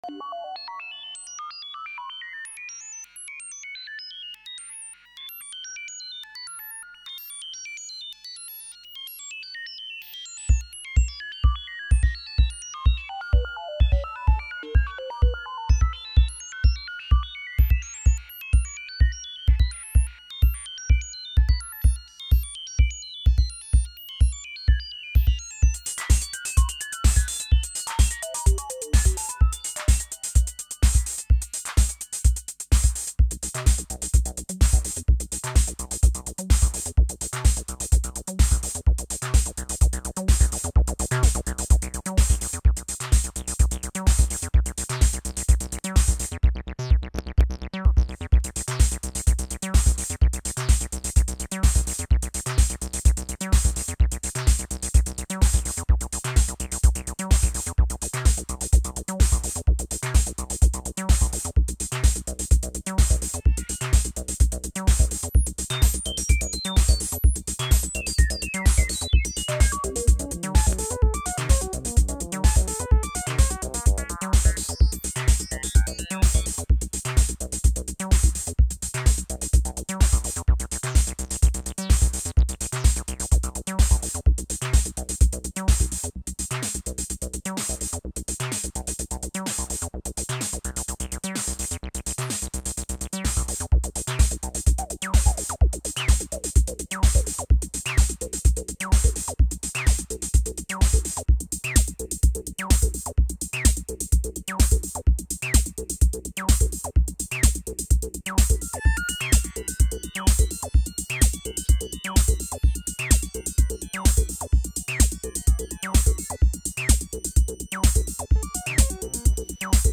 Jamming with modular gear, rd6, to-3-mo and a hand built reverb